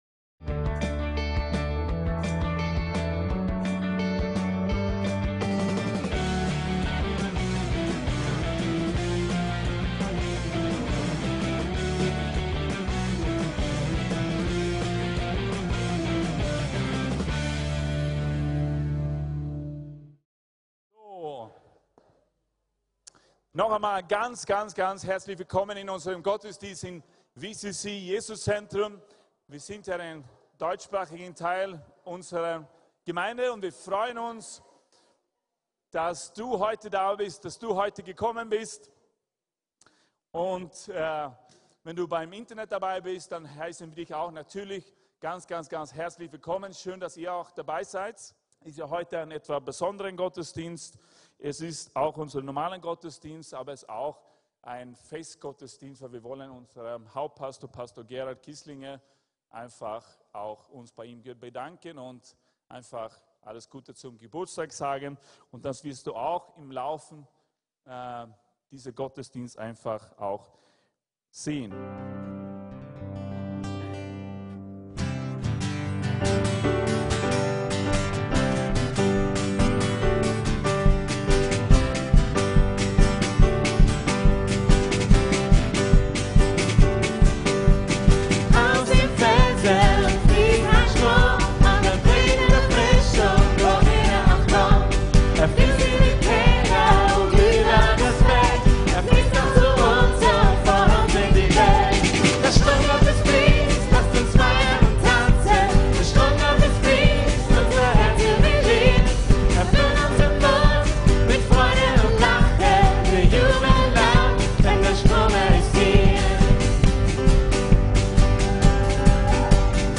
Gottesdienste